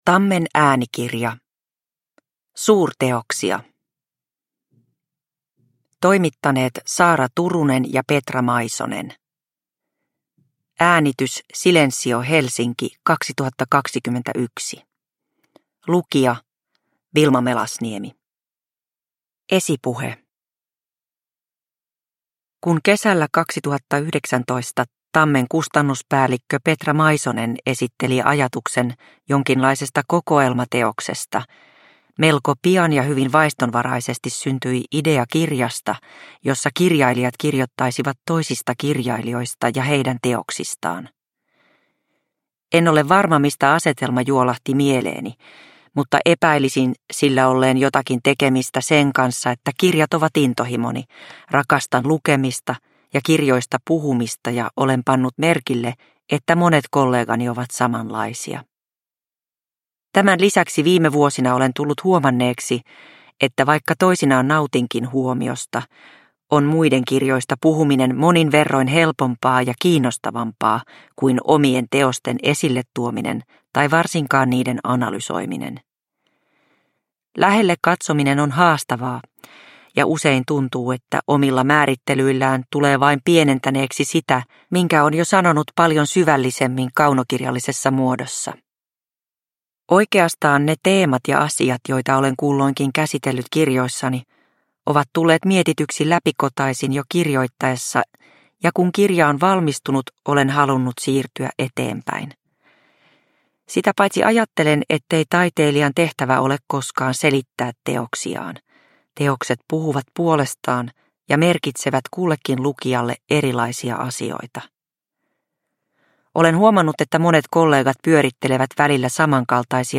Suurteoksia – Ljudbok – Laddas ner